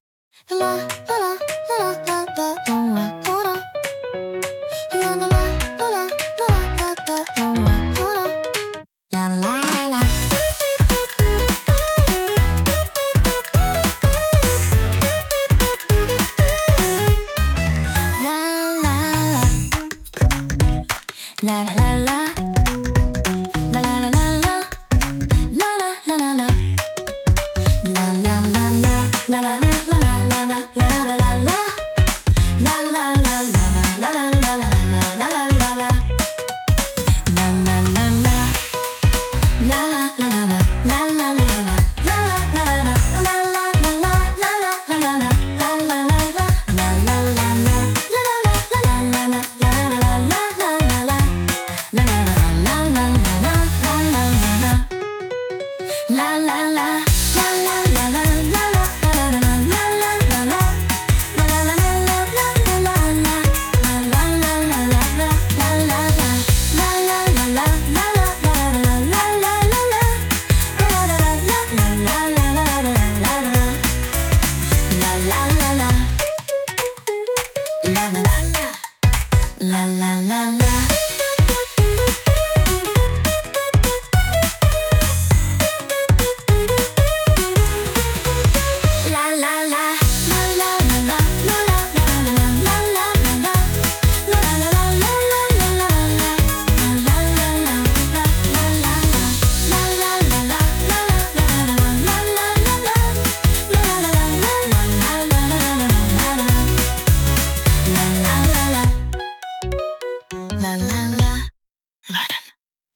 会場中をハッピーな空気で満たす、とっても可愛いポップチューン！
一番の特徴は、女性ボーカルが歌う「ラララ〜」というキャッチーなメロディ。